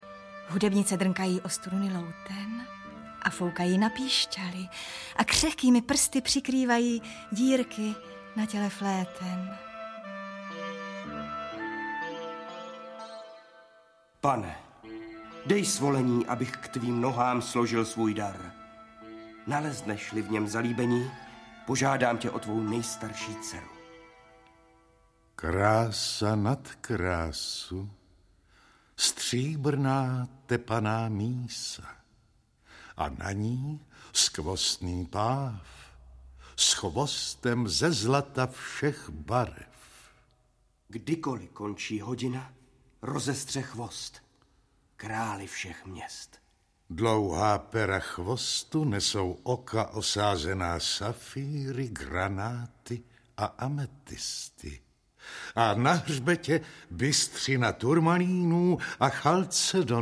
Audiobook
Read: Jan Vlasák